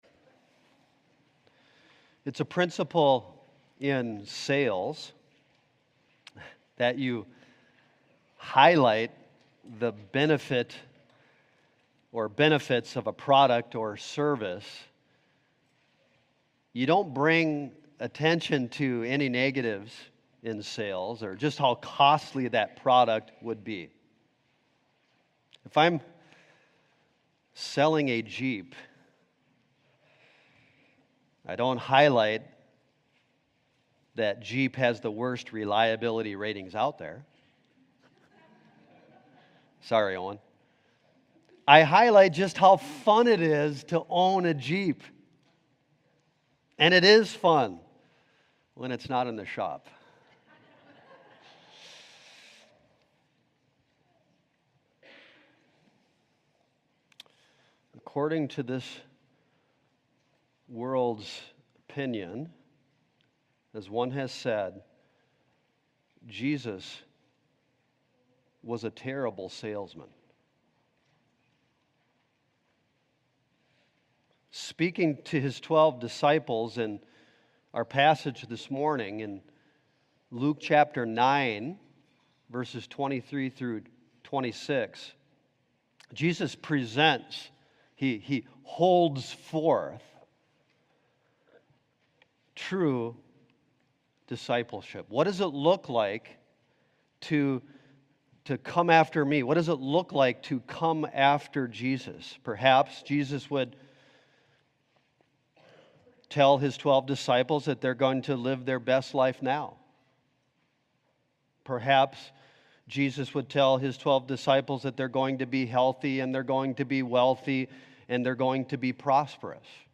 If Anyone Comes After Me | SermonAudio Broadcaster is Live View the Live Stream Share this sermon Disabled by adblocker Copy URL Copied!